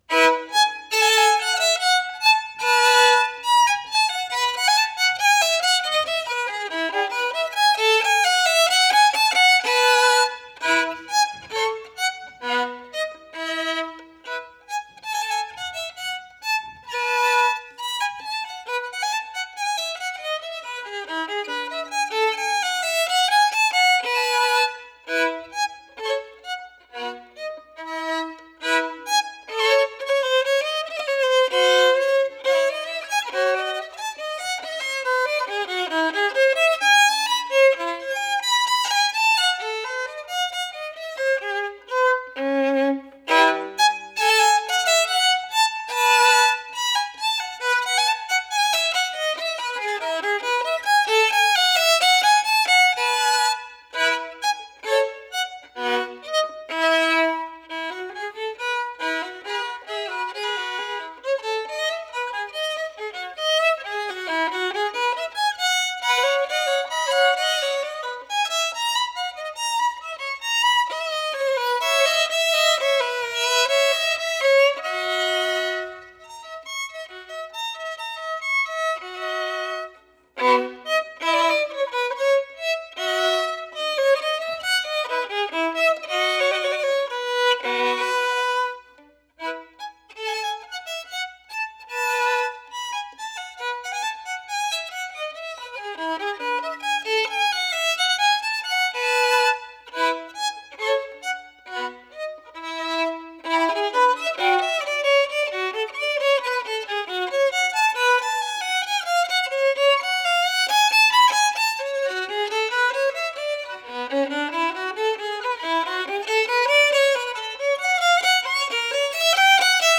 Producción de un CD con las obras interpretadas por los estudiantes de recitales, ensamble de jazz, música ecuatoriana y música Latinoamericana de la Universidad de Los Hemisferios del semestre 2017-1 de mayor calidad sonora y mejor ejecución musical